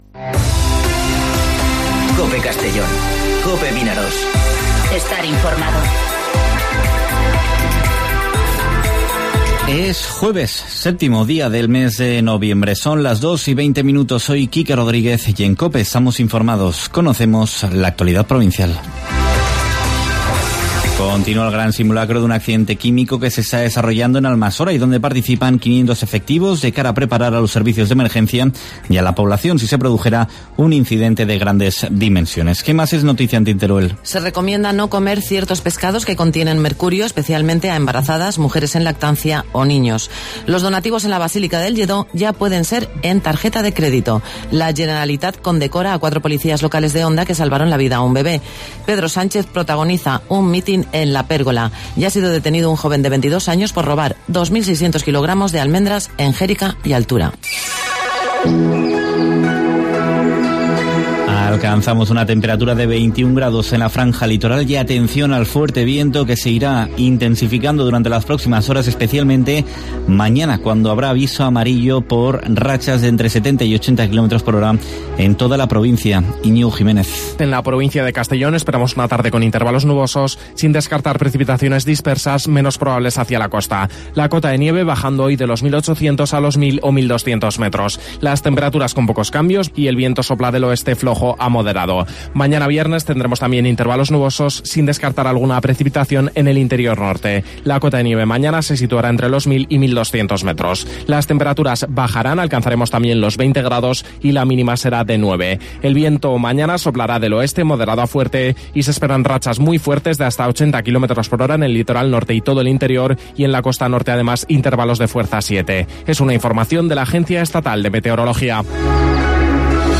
Informativo Mediodía COPE en Castellón (07/11/2019)